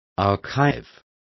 Also find out how archivo is pronounced correctly.